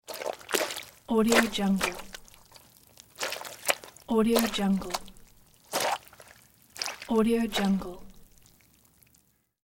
دانلود افکت صدای خارج شدن کف از مخزن
افکت صدای خارج شدن کف از مخزن یک گزینه عالی برای هر پروژه ای است که به صداهای کارتونی و جنبه های دیگر مانند squish، goop و slime نیاز دارد.
Sample rate 16-Bit Stereo, 44.1 kHz
Looped No